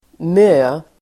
Ladda ner uttalet
Uttal: [mö:]